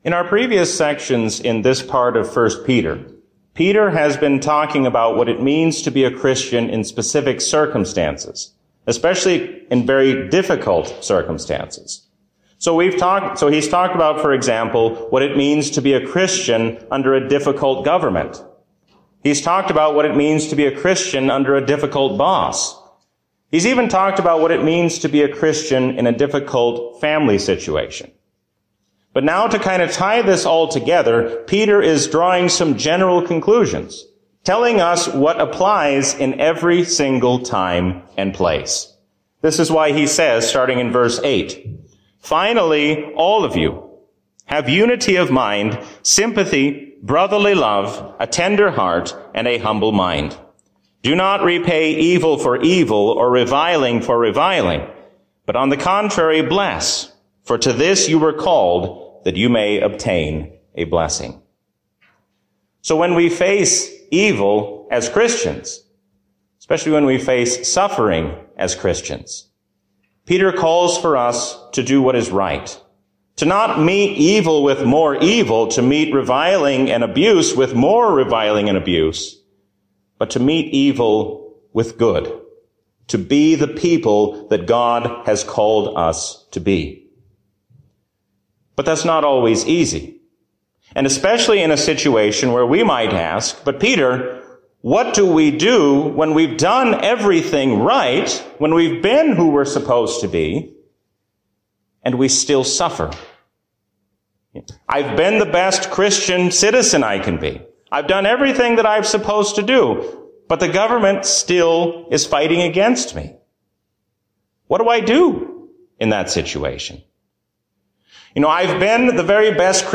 St. Peter and Zion Lutheran
Sermon